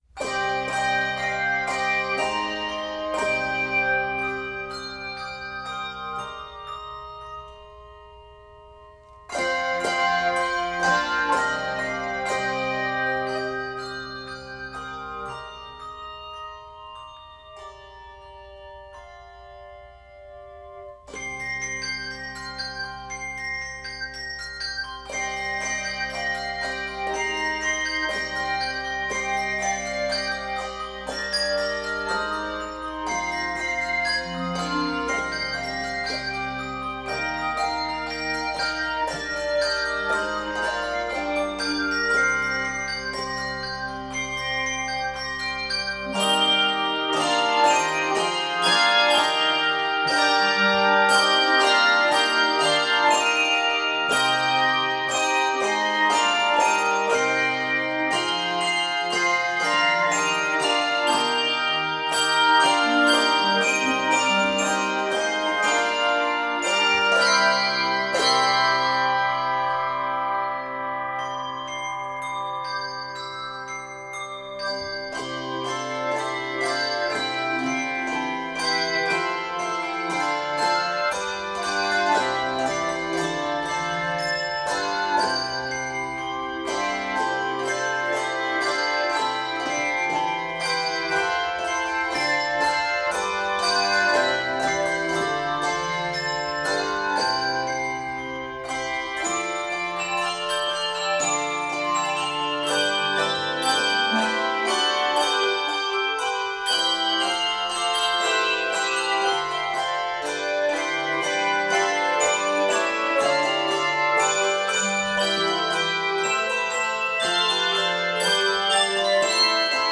triumphant medley of three cherished Easter hymns
Keys of c minor, C Major, and Eb Major.